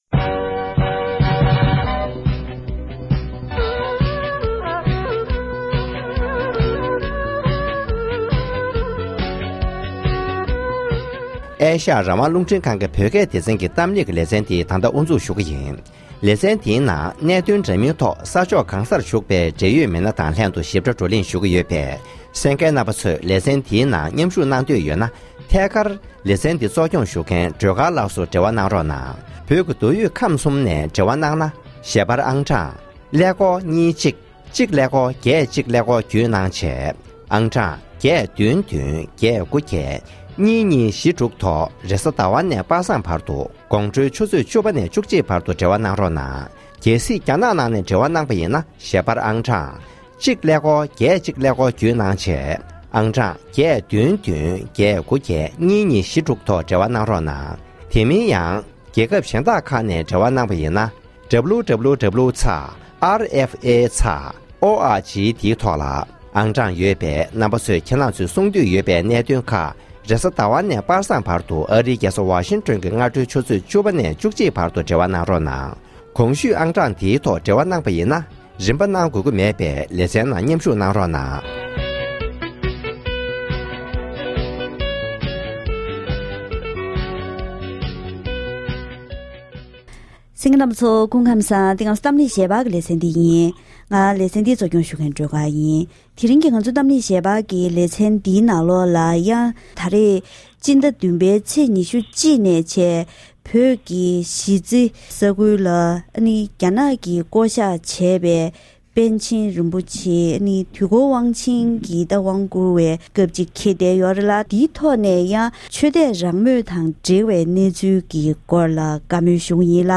བོད་ནང་གི་ཆོས་དད་རང་མོས་སྐོར་བགྲོ་གླེང་གནང་བ།